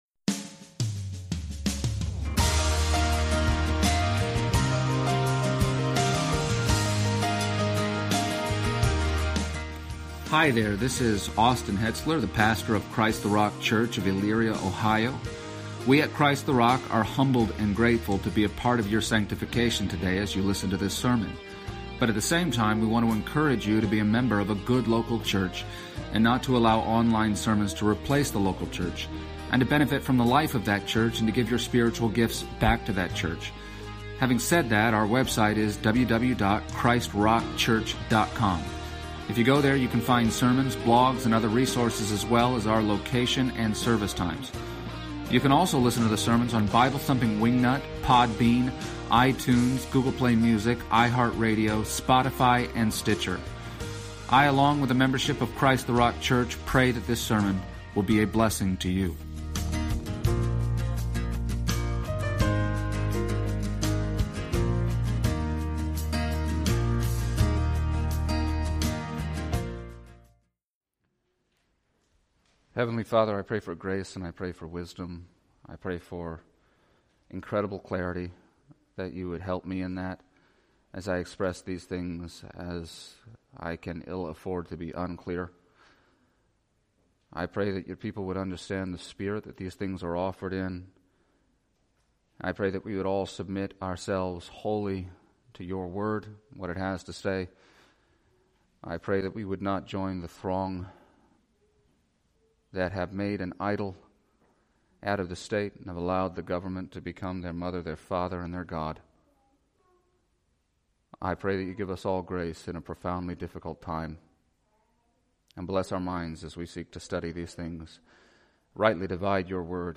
and the Christian Service Type: Sunday Morning %todo_render% « Creation and the Gospel